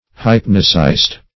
Search Result for " hypnocyst" : The Collaborative International Dictionary of English v.0.48: Hypnocyst \Hyp"no*cyst\, n. [Gr.
hypnocyst.mp3